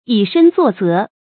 注音：ㄧˇ ㄕㄣ ㄗㄨㄛˋ ㄗㄜˊ
以身作則的讀法